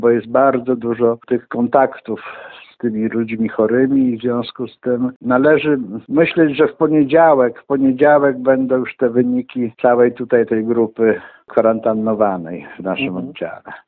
– Na miejscu pracują dwa zespoły pobierania materiału do badań – mówi Jerzy Nikliński, Powiatowy Inspektor Sanitarny w Grajewie.